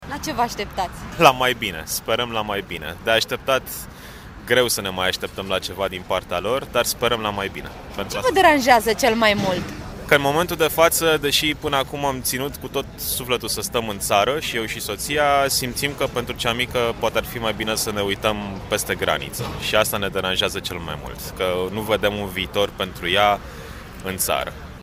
Sute de oameni s-au adunat, la ora 18.00, în Piața Victoriei.
A stat de vorbă cu protestatarii.